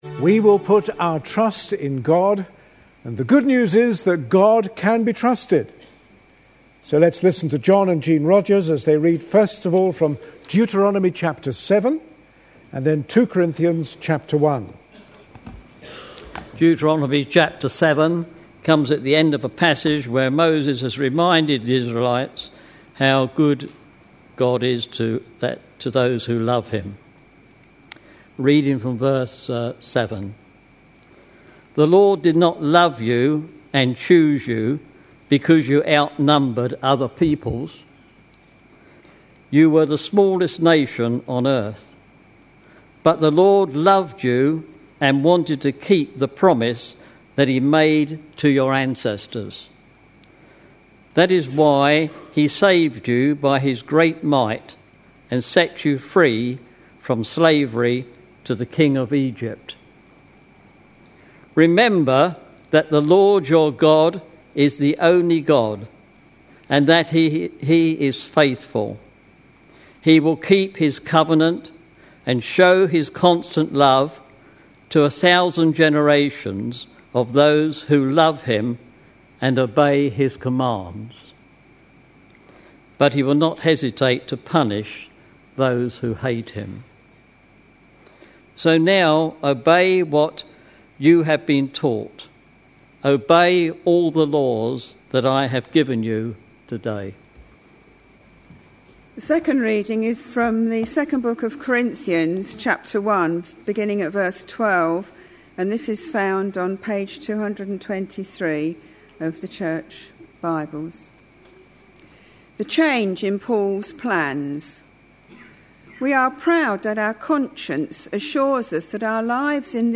A sermon preached on 29th May, 2011, as part of our God At Work In Our Lives. series.